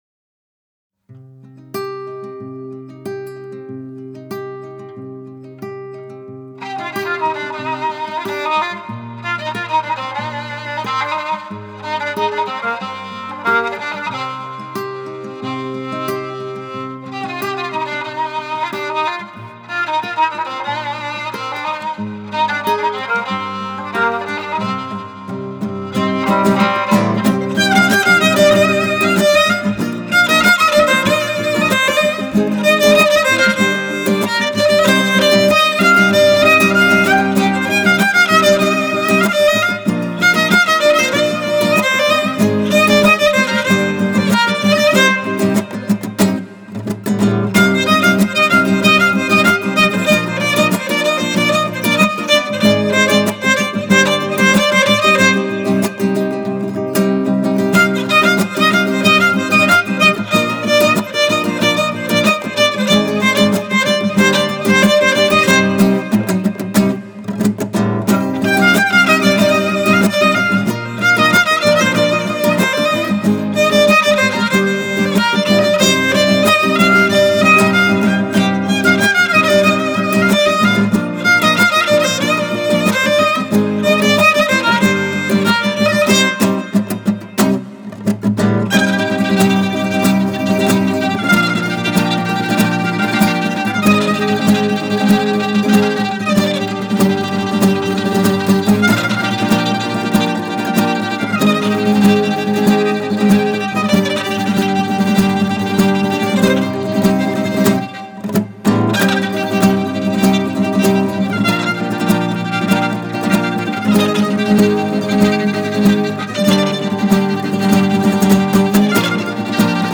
یک قطعه موسیقی بی‌کلام